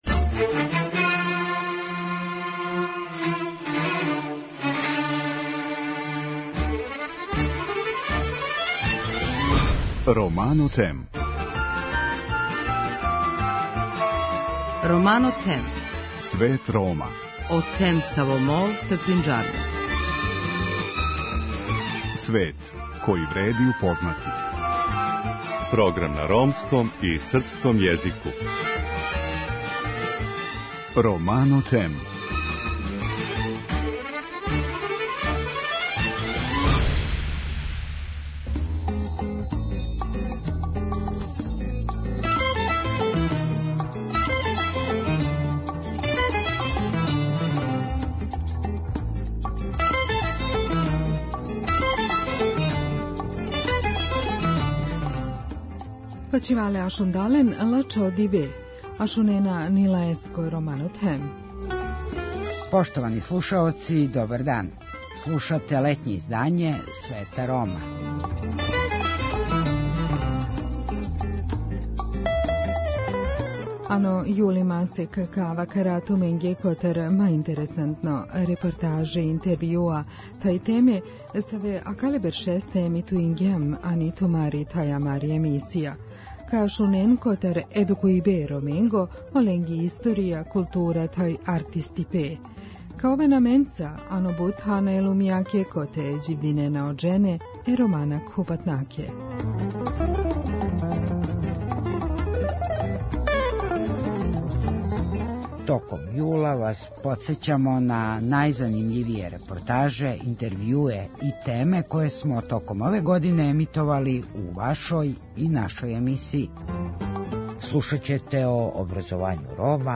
Током разговора слушаћемо њихове нумере које постају све популарније.